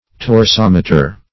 What does torsometer mean? Meaning of torsometer. torsometer synonyms, pronunciation, spelling and more from Free Dictionary.
torsometer.mp3